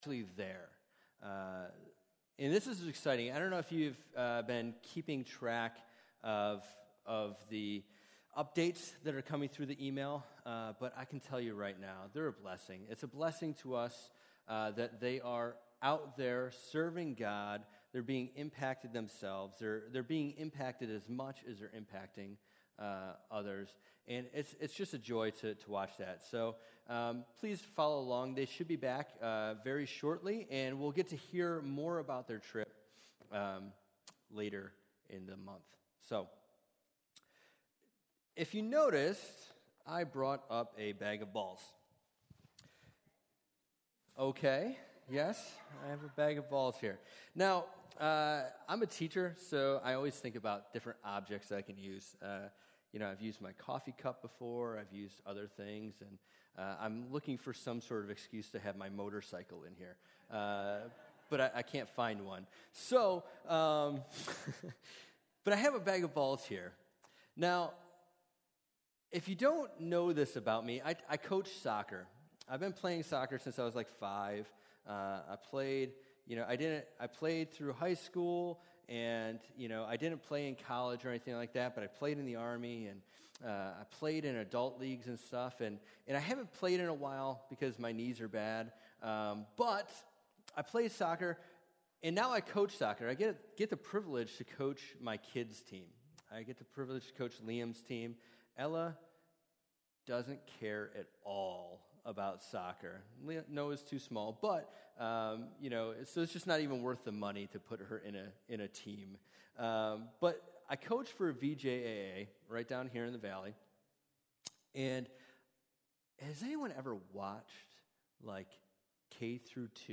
Sermons | Syracuse Alliance Church